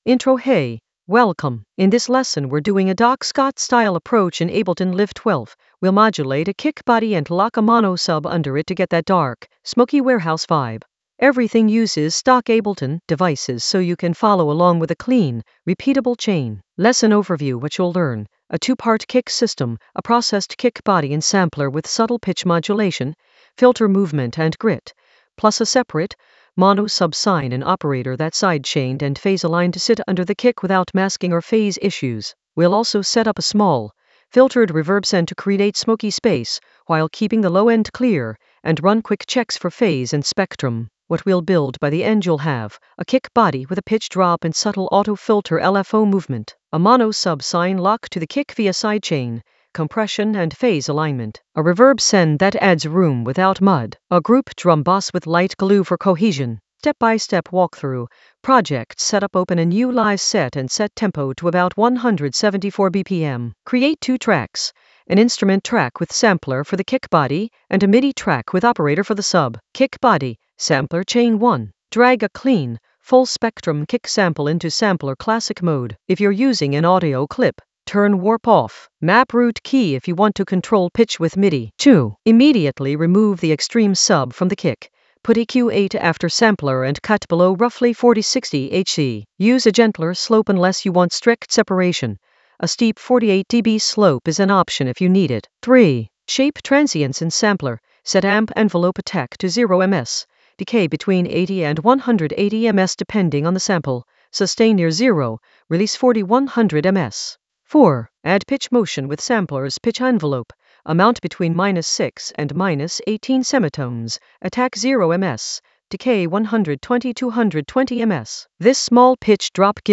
An AI-generated beginner Ableton lesson focused on Doc Scott approach: modulate a kick and sub lock in Ableton Live 12 for smoky warehouse vibes in the Edits area of drum and bass production.
Narrated lesson audio
The voice track includes the tutorial plus extra teacher commentary.